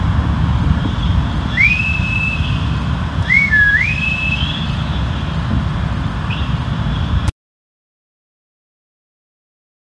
Свистят в свисток
whistle1.wav